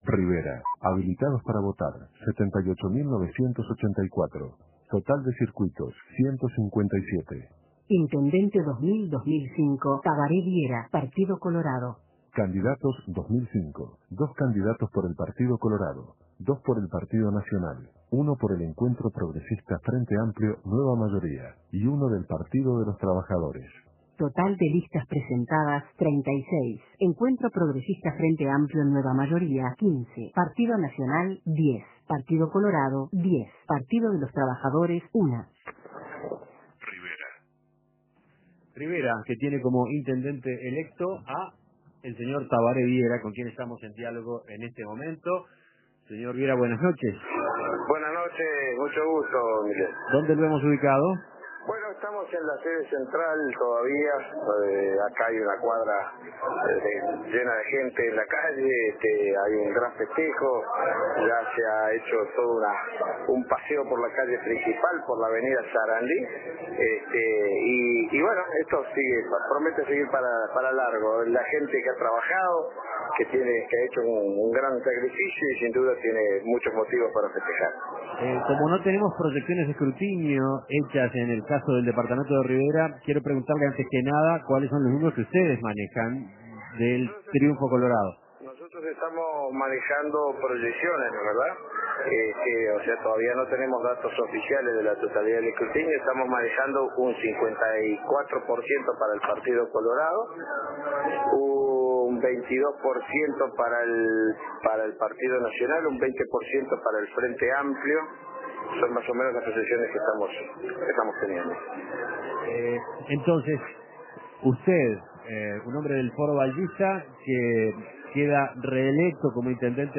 Entrevistas Rivera, el único municipio colorado Imprimir A- A A+ En un hecho histórico, el Partido Colorado obtuvo una sola intendencia municipal.